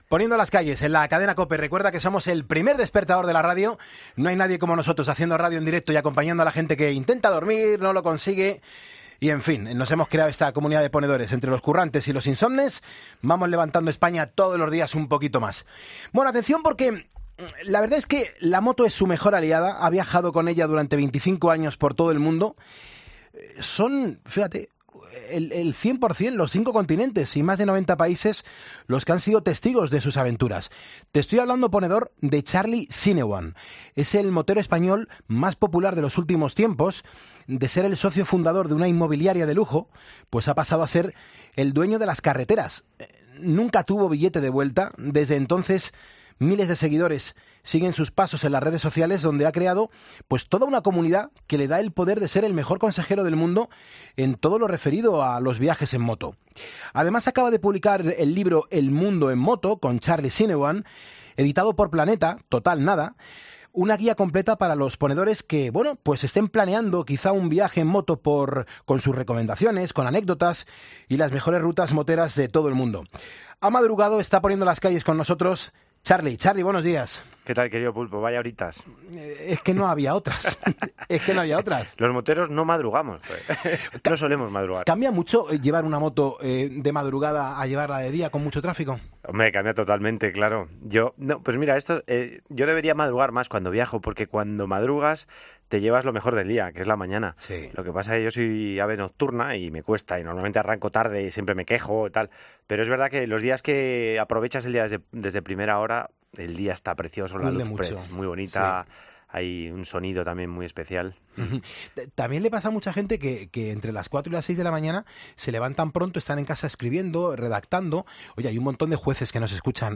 Entrevistas en Poniendo las calles